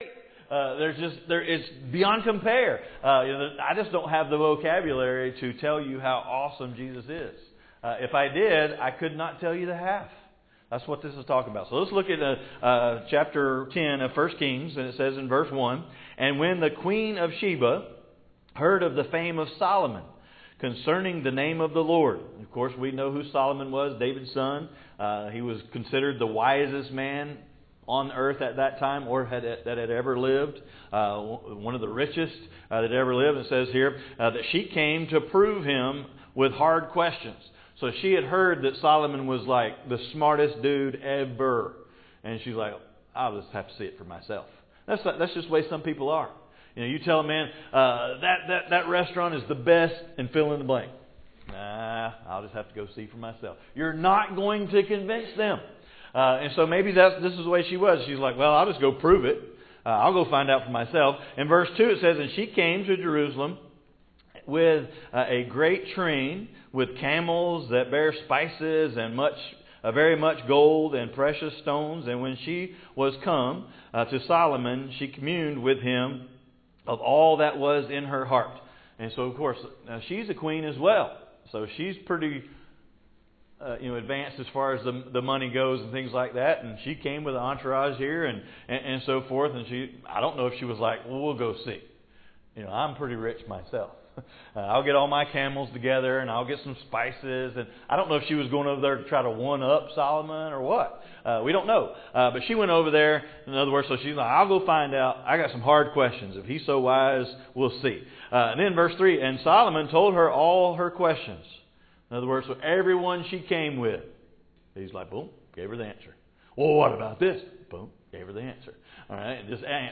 Passage: I Kings 10:7 Service Type: Morning Service